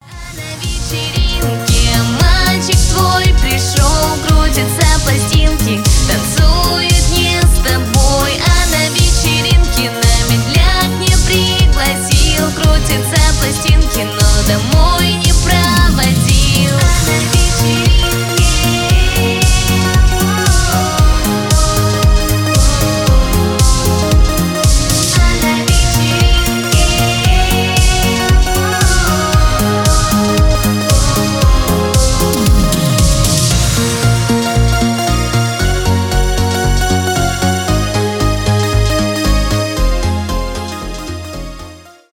грустные
поп
танцевальные
диско